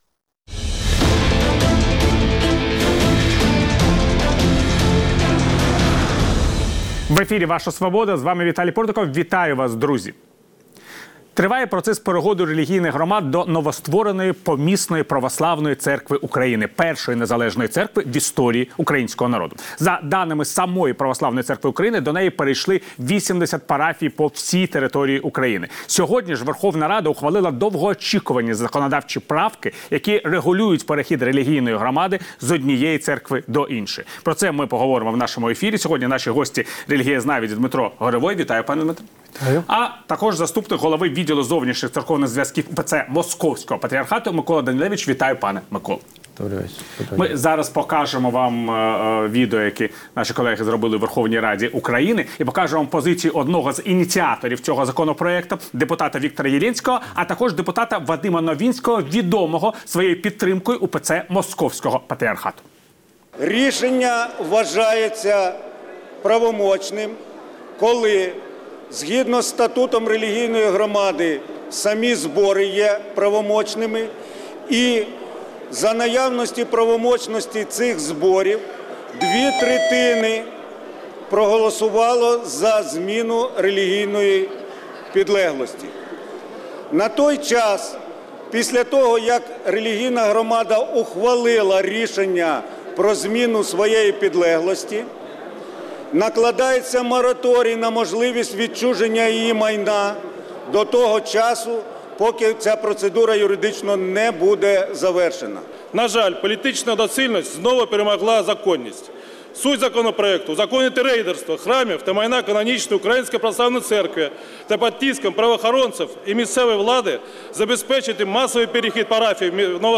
релігієзнавець